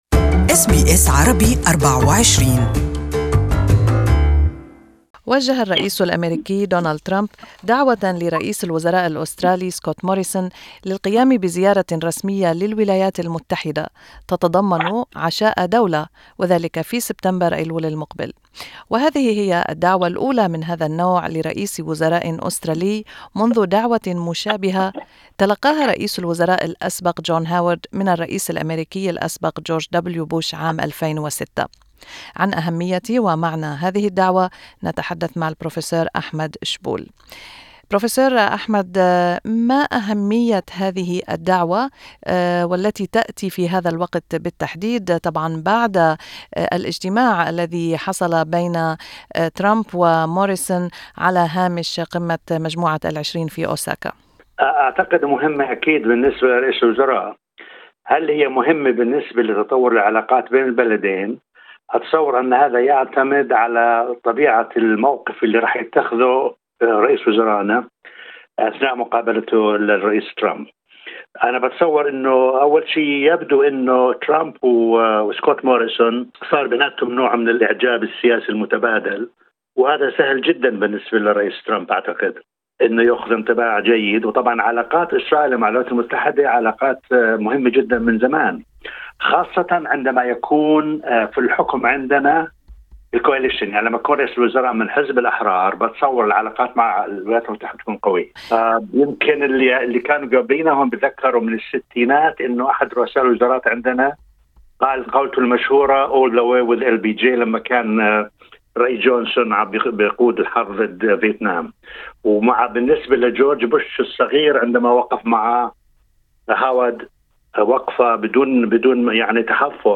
استمعوا إلى اللقاء كاملا تحت الشريط الصوتي.